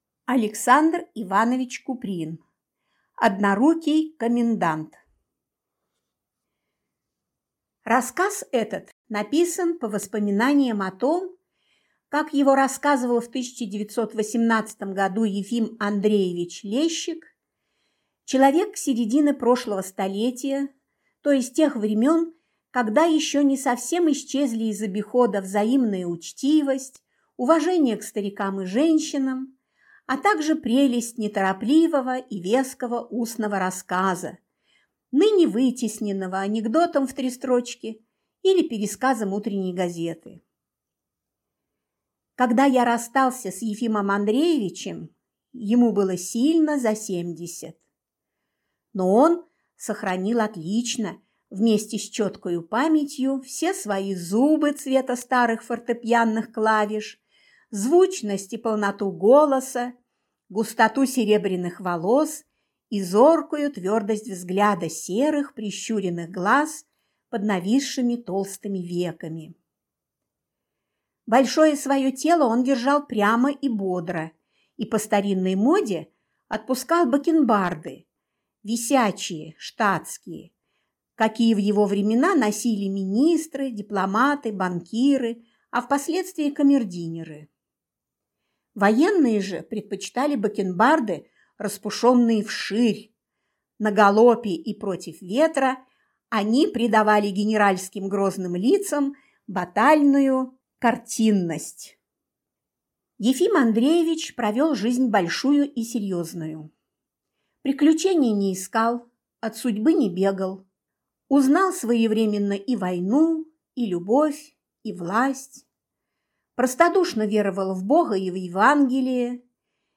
Аудиокнига Однорукий комендант | Библиотека аудиокниг